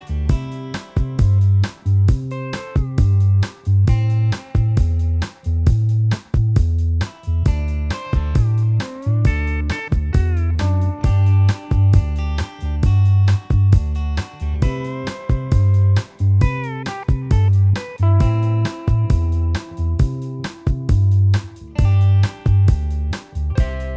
Minus Acoustic Guitars Soft Rock 6:19 Buy £1.50